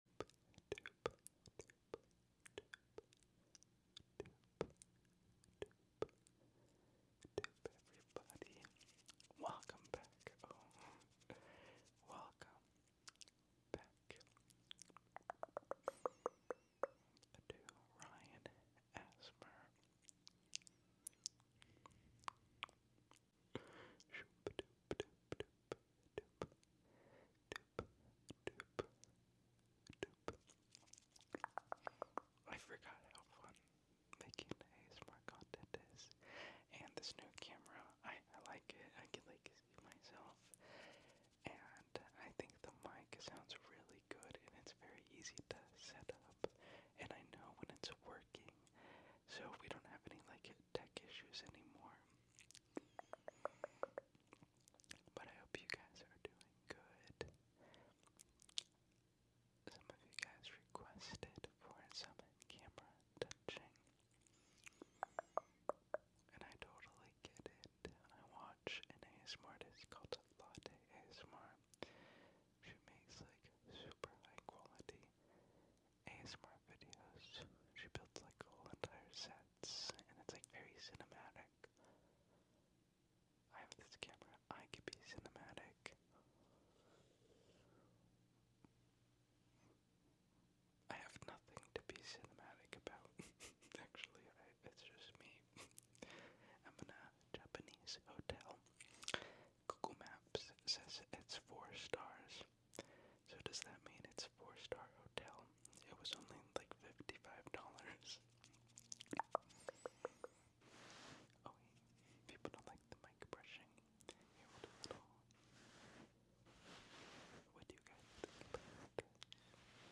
ASMR That will help you sound effects free download
ASMR That will help you sleep and relax.